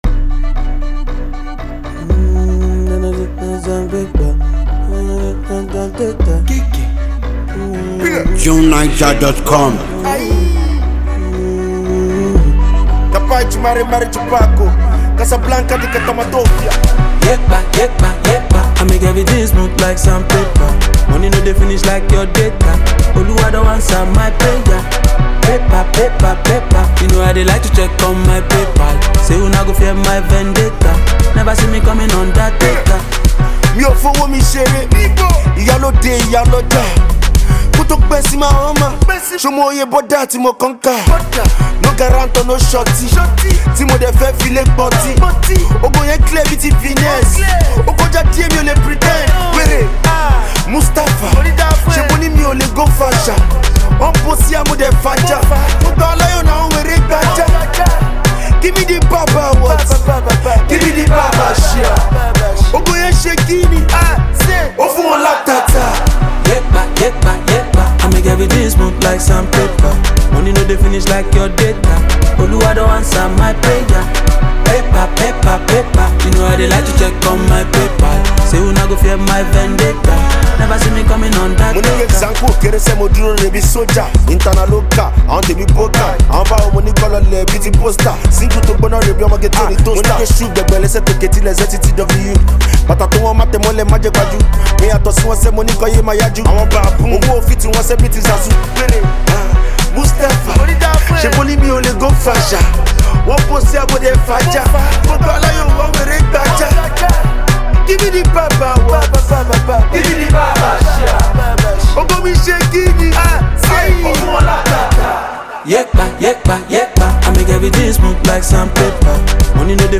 the award-winning Nigerian rap artist